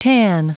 Prononciation du mot tan en anglais (fichier audio)
Prononciation du mot : tan